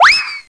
SFX丢东西下载音效下载
SFX音效